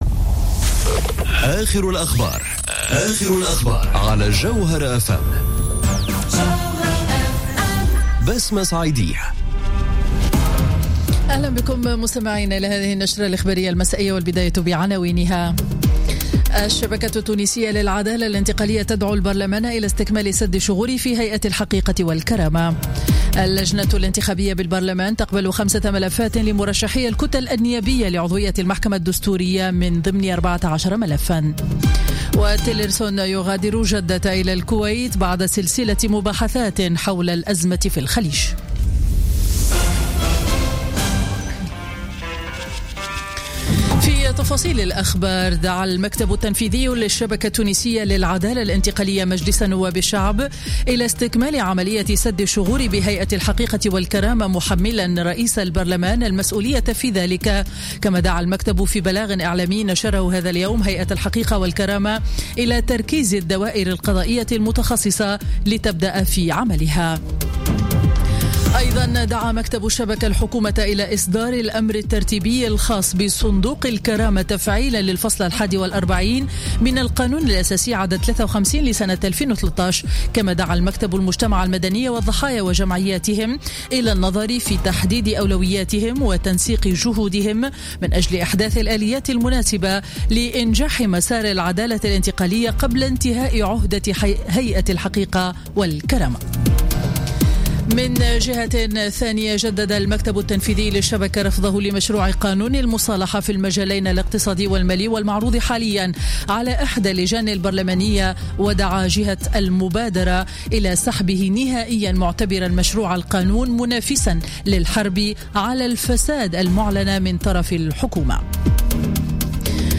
نشرة السابعة مساء ليوم الأربعاء 12 جويلية 2017